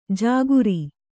♪ jāguri